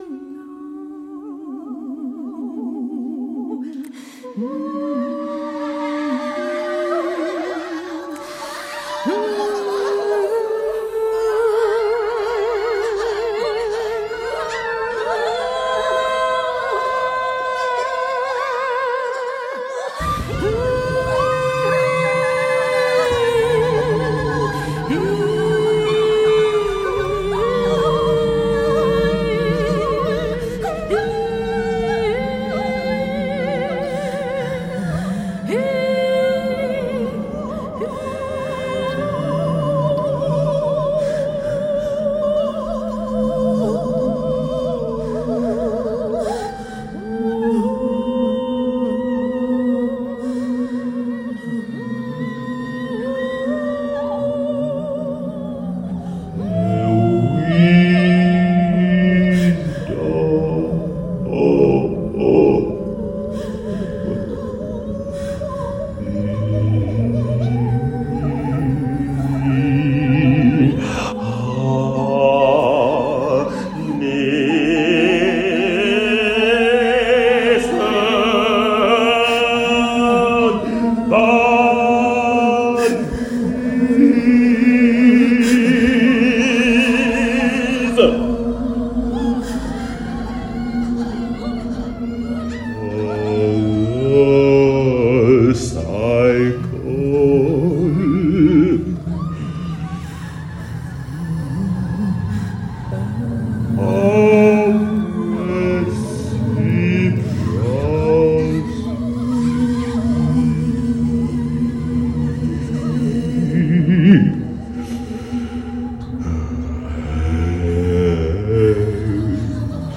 recorded live on set